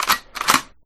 gun.wav